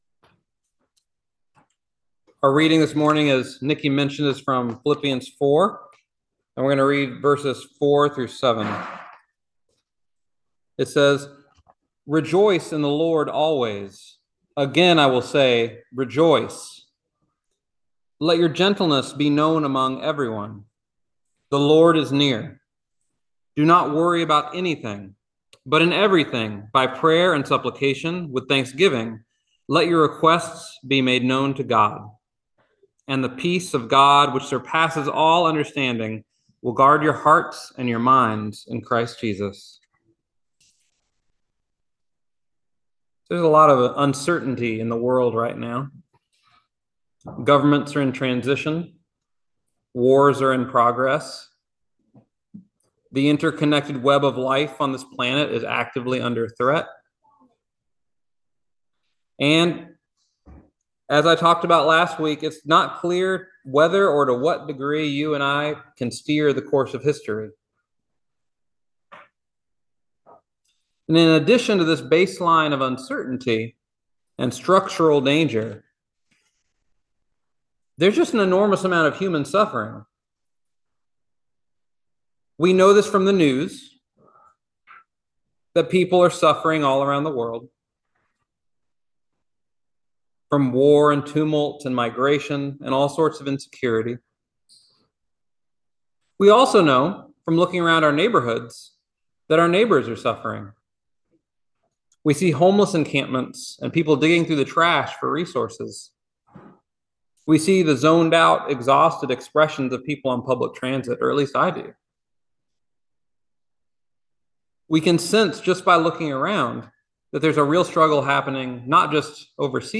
Listen to the most recent message from Sunday worship at Berkeley Friends Church, “The Lord Is Near.”